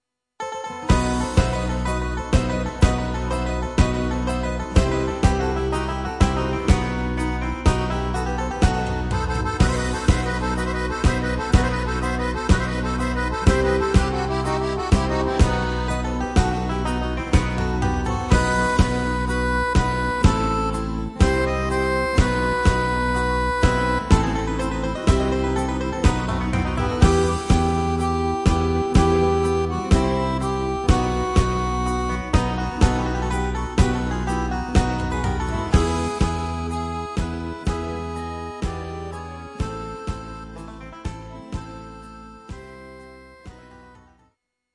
Greek Zeimpekiko